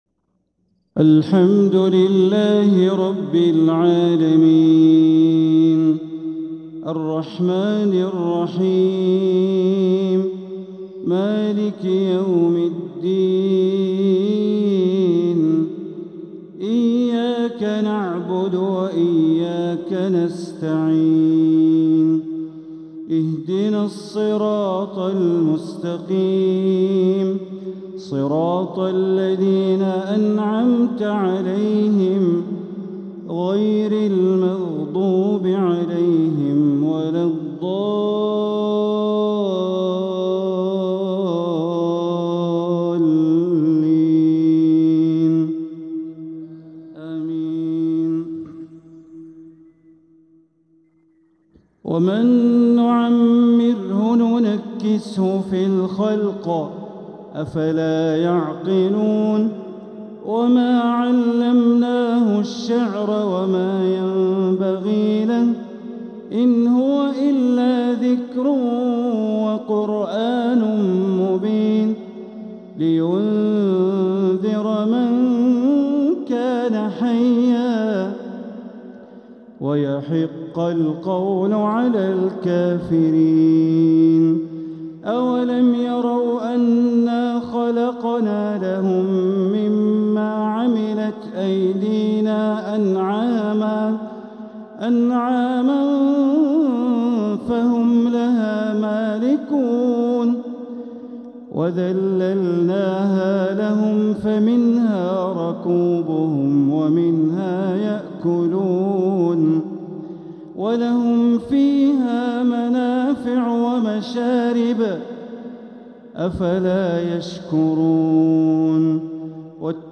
تلاوة من سورة يس مغرب الإثنين ٢-٣-١٤٤٧ > 1447هـ > الفروض - تلاوات بندر بليلة